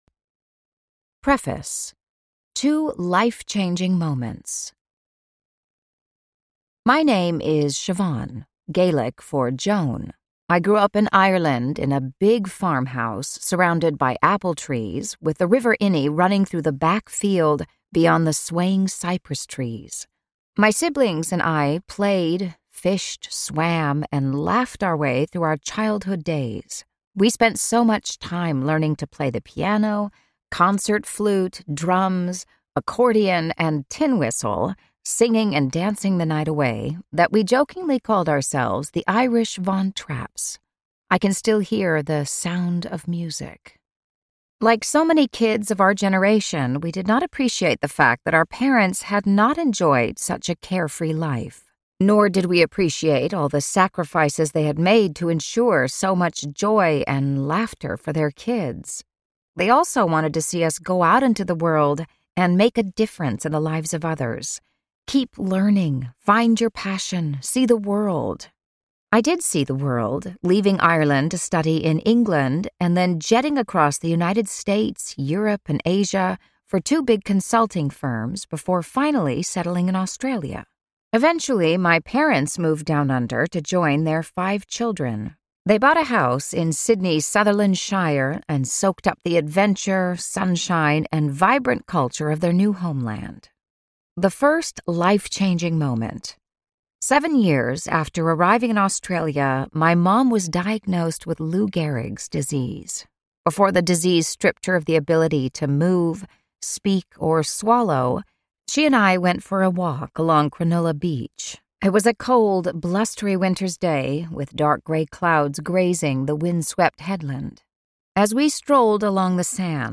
Narrator
6.8 Hrs. – Unabridged